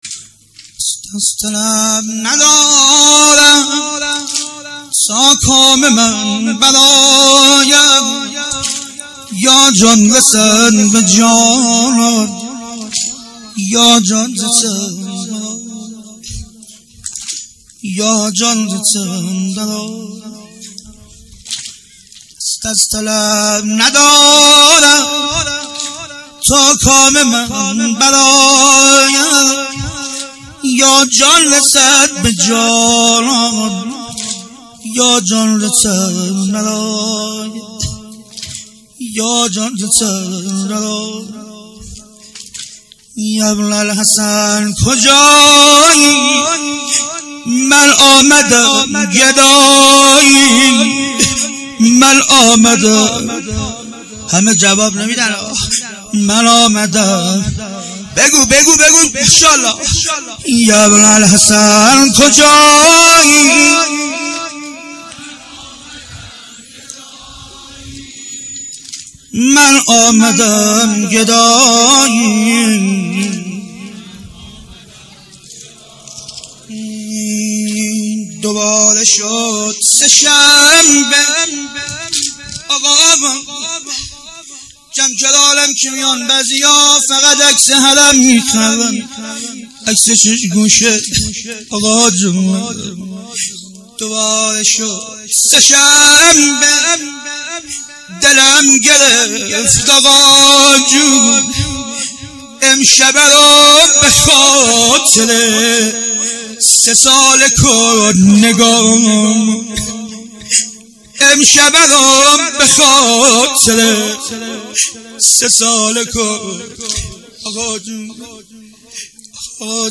دست از طلب ندارم - نوحه امام زمان